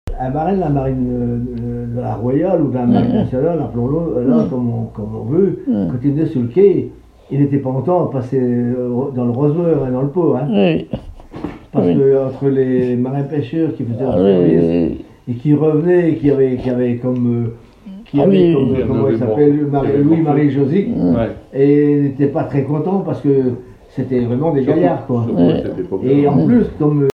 Enquête Douarnenez en chansons
Catégorie Témoignage